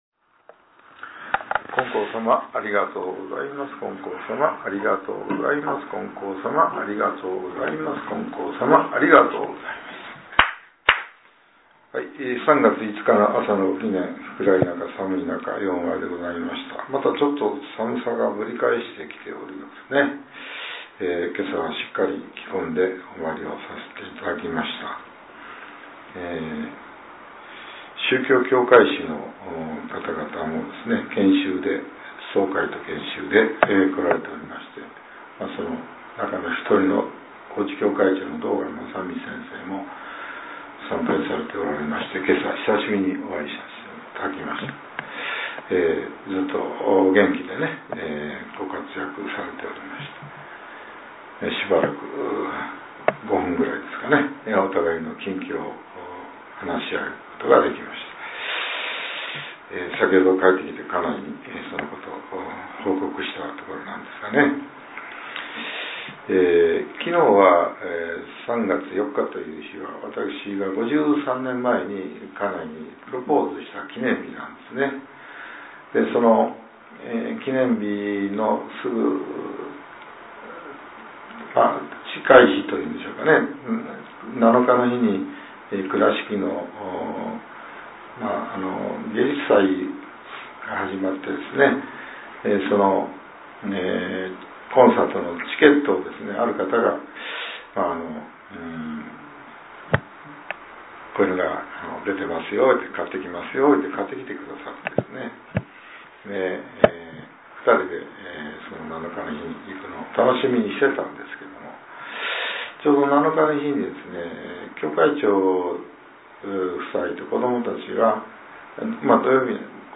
令和８年３月５日（朝）のお話が、音声ブログとして更新させれています。